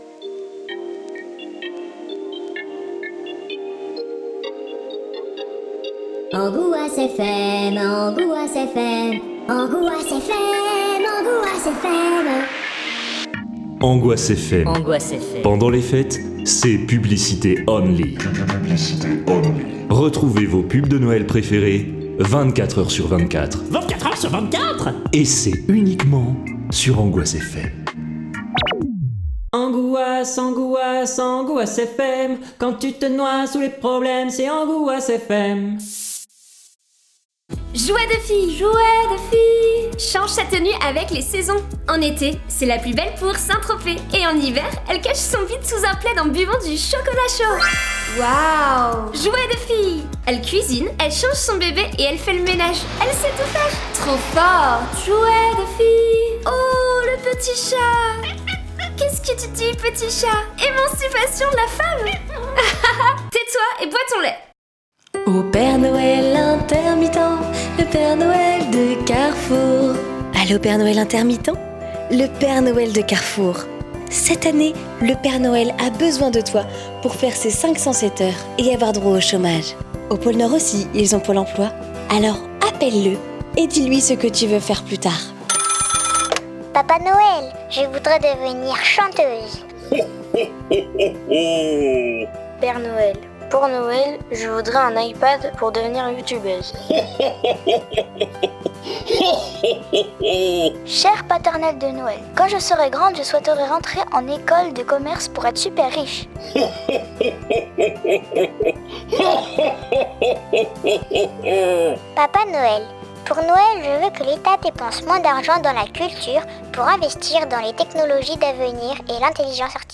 Si vous êtes ici c’est pour entendre une partie de JDR horrifique…